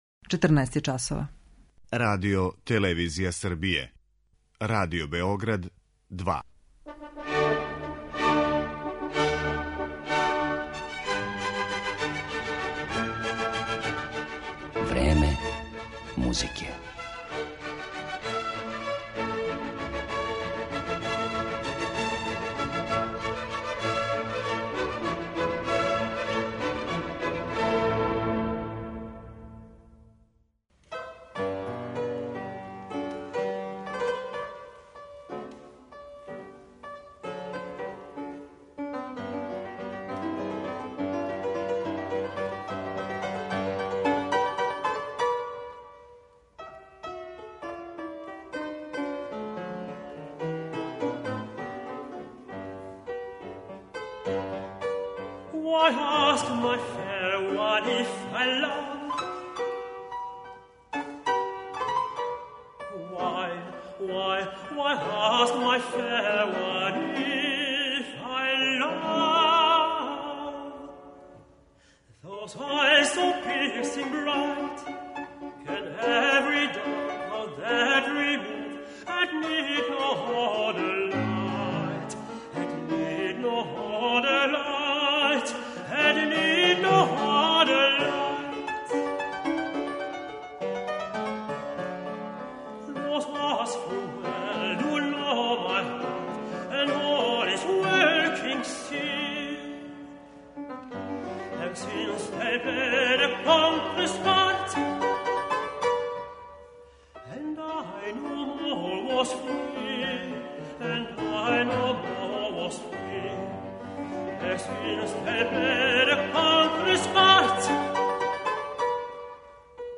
белгијски тенор
уз бугарског пијанисту